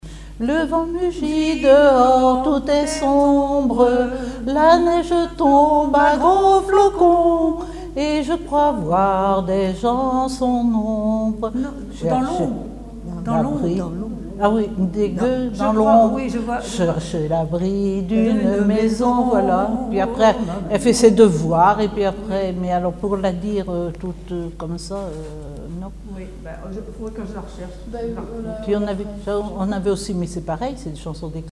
Mémoires et Patrimoines vivants - RaddO est une base de données d'archives iconographiques et sonores.
Genre strophique
Chansons et formulettes enfantines
Pièce musicale inédite